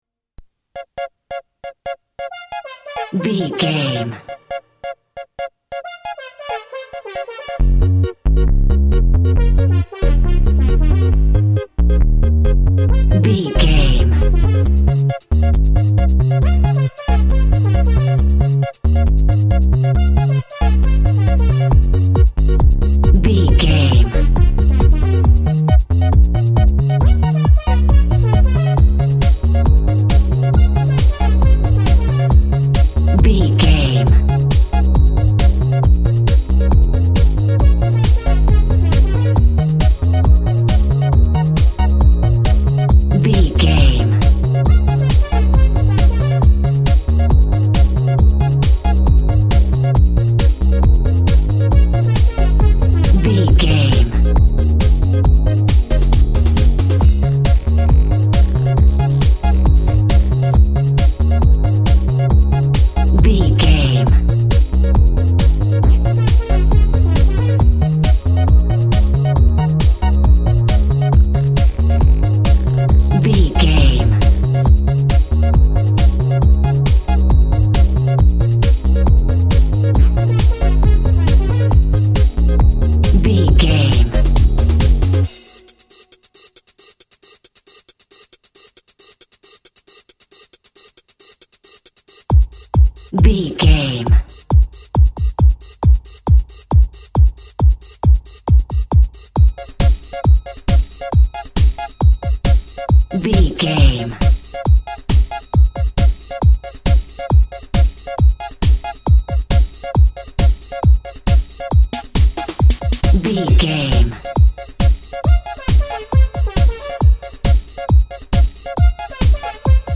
Ionian/Major
D♭
joyful
cheerful/happy
energetic
synthesiser
drum machine
techno
synth lead
synth bass
Synth Pads